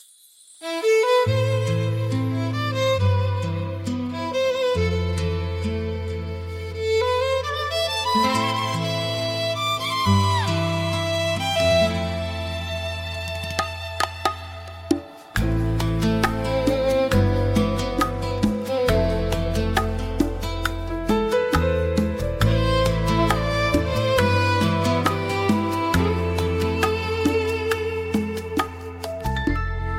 Nuevas sonoridades cubanas.